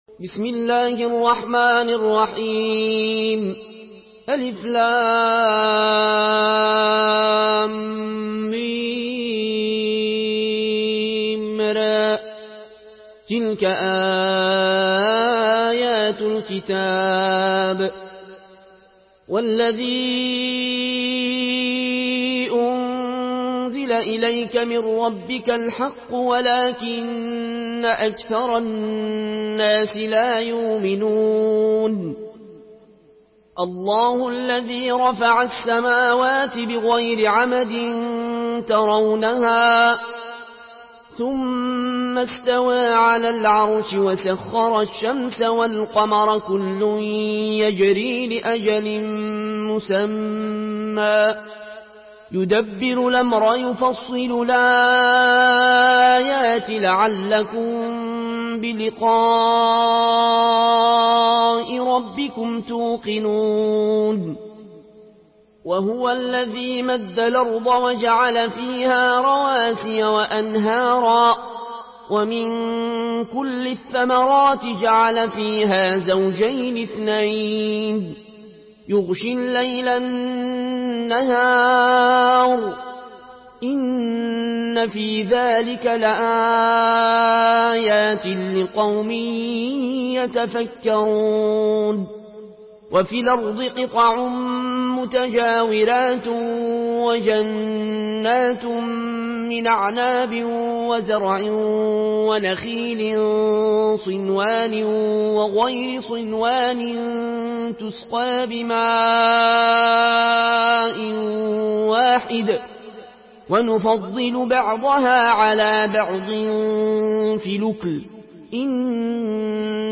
Murattal Warsh An Nafi From Al-Azraq way